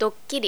pan', bread and geminate consonants, e.g. '
dokkiri.wav